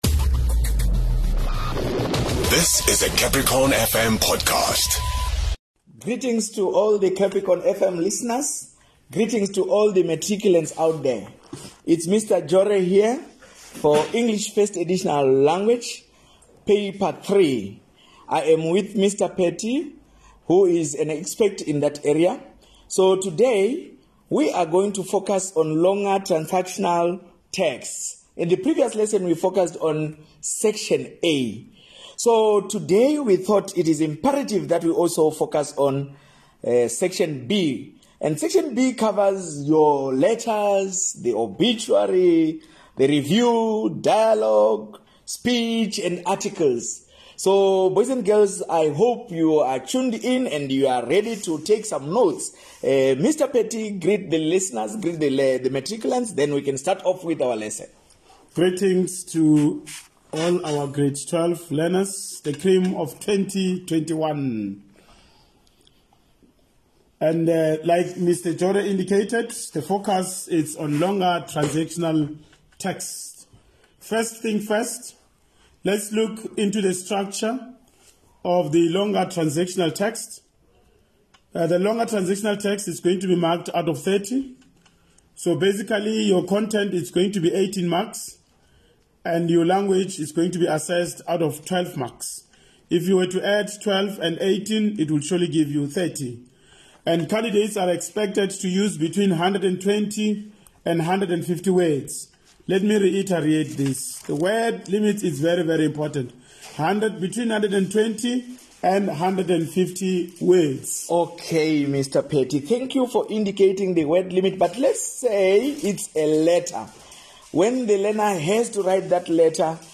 As the year edges to an end, the Limpopo Department of Basic Education has dedicated time everyday on CapricornFM to helping Grade12 learners catch up on various lessons